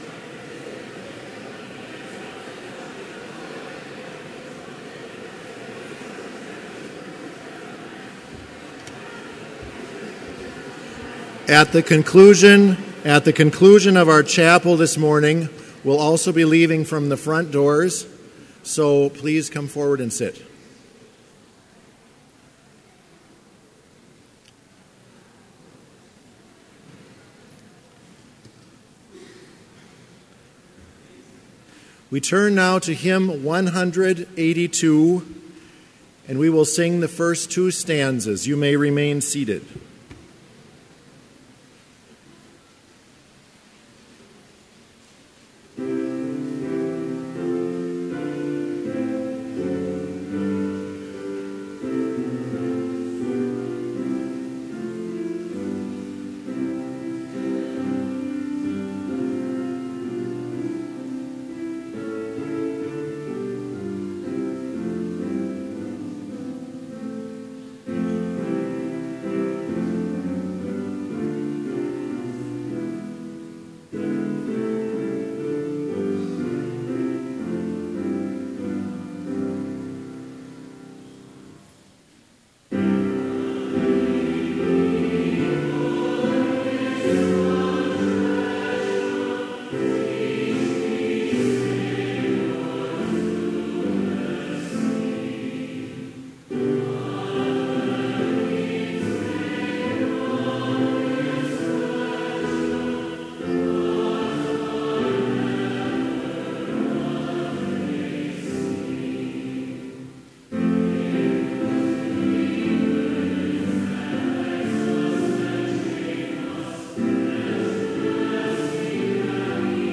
Complete service audio for Chapel - August 26, 2016